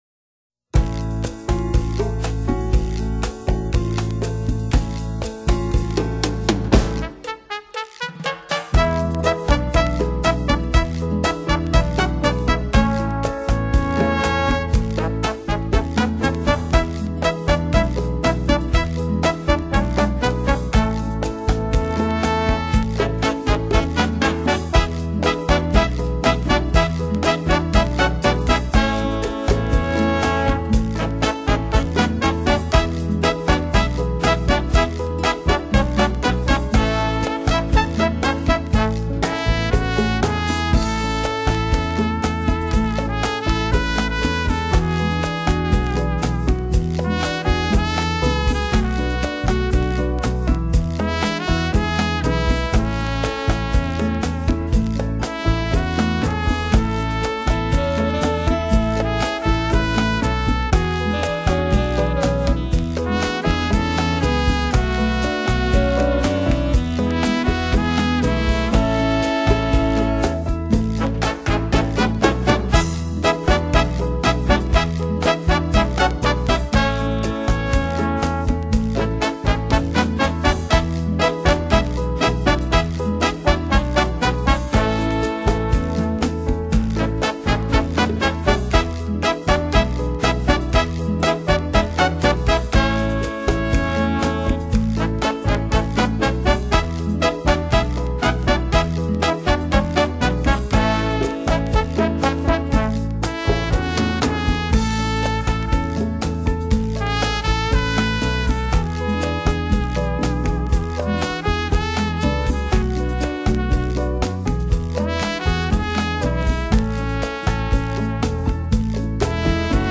Latin, Instrumental